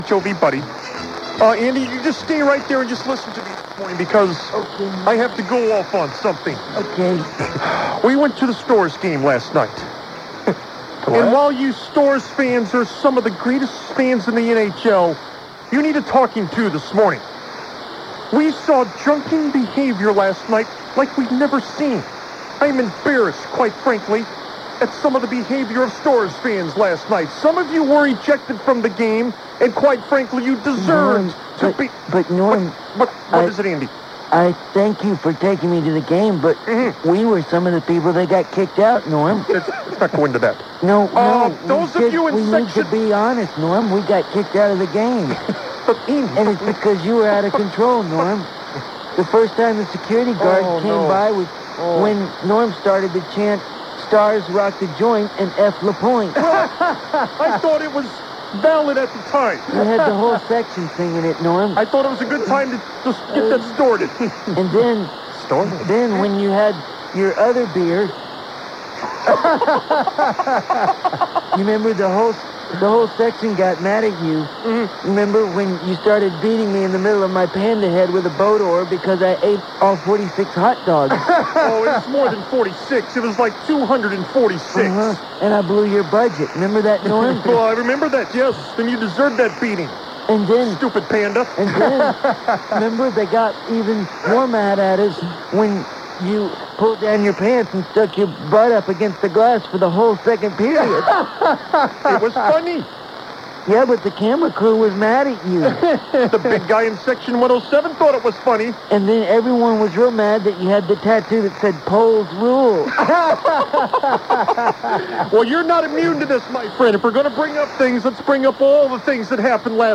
Forgive the audio quality here.